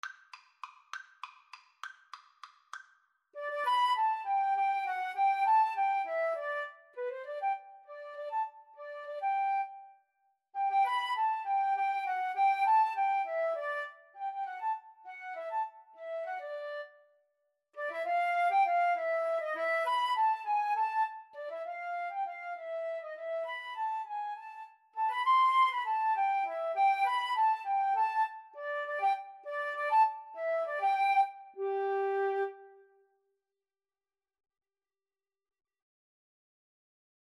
3/8 (View more 3/8 Music)
Classical (View more Classical Flute Duet Music)